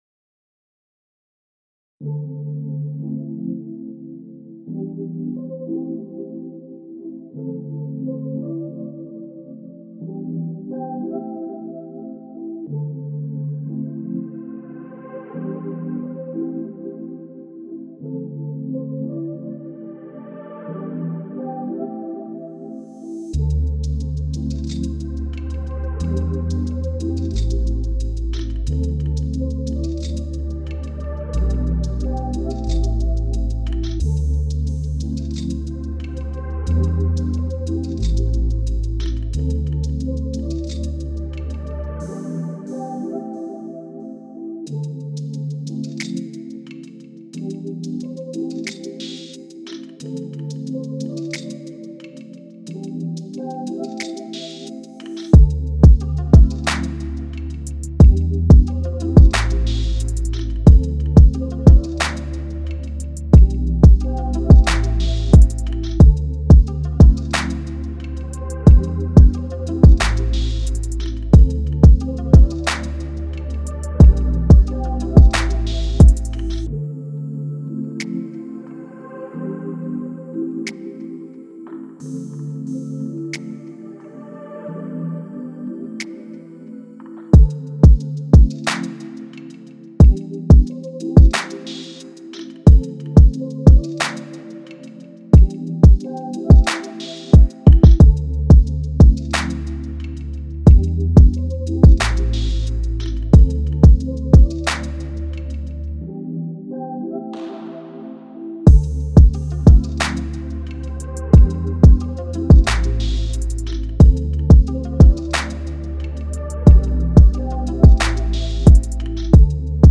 Description : Hip Hop with an R&B blend